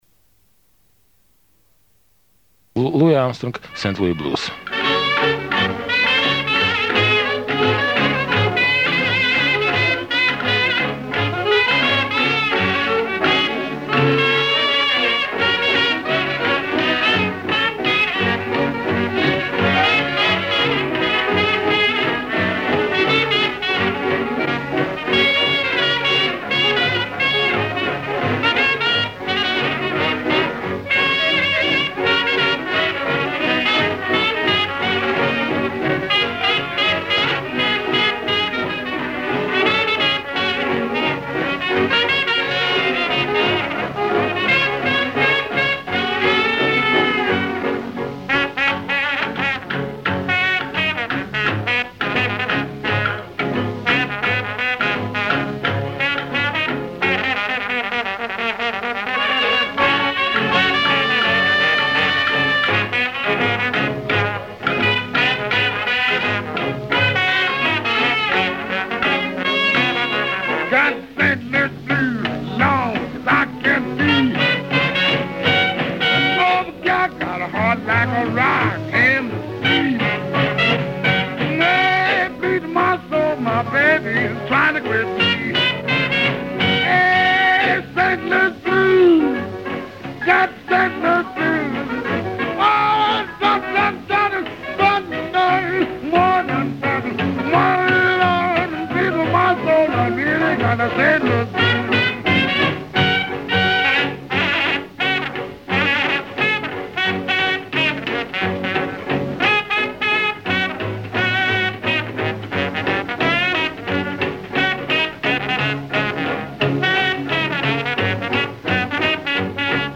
Спасибо за музыку. восхищаюсь вашей коллекцией старых записей
Джаз